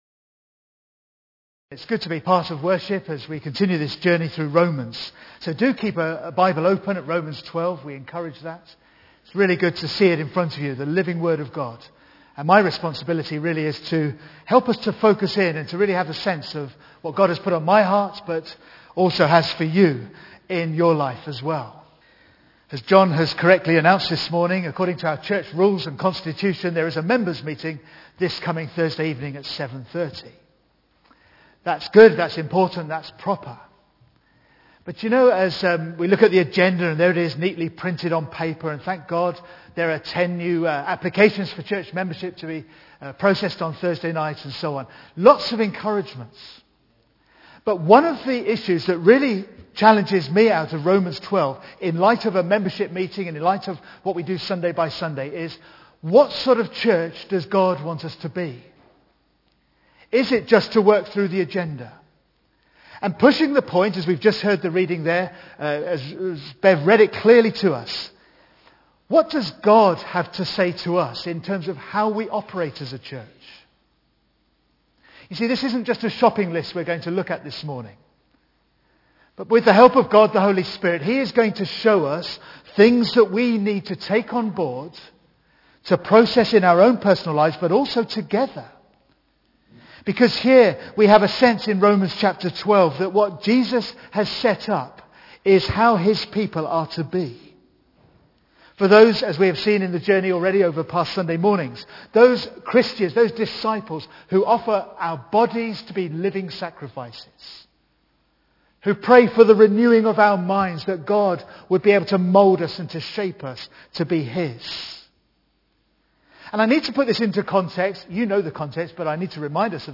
2012 Service type: Sunday AM Bible Text